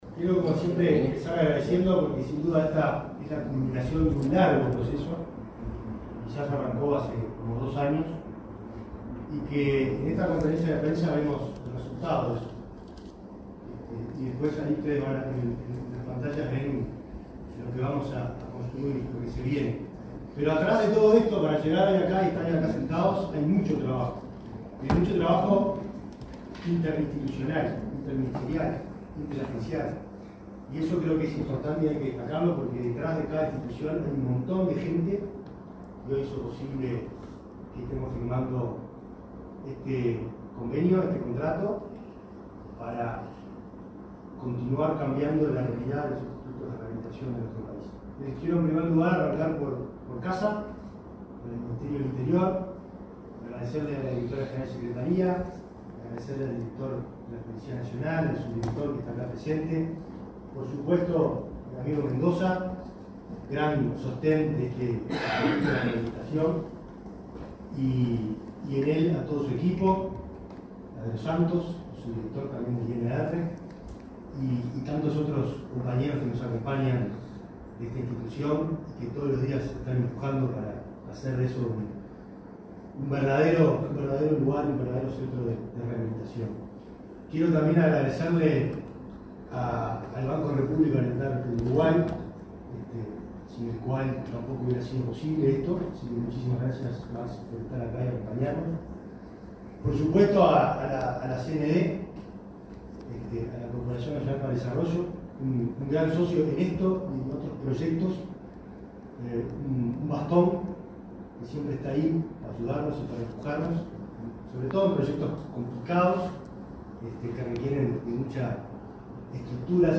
Palabras del ministro del Interior, Nicolás Martinelli
En el marco de la firma de un contrato de participación público-privada para la construcción de una cárcel para mujeres en Punta de Rieles, se expresó